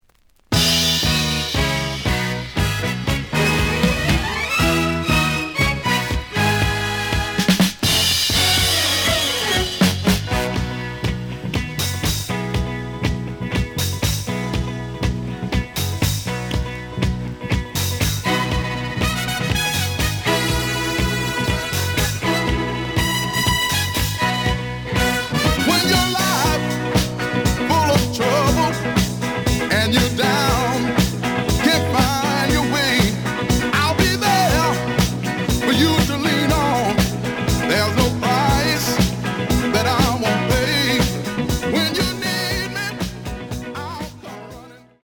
The audio sample is recorded from the actual item.
●Genre: Disco
Slight damage on both side labels. Plays good.)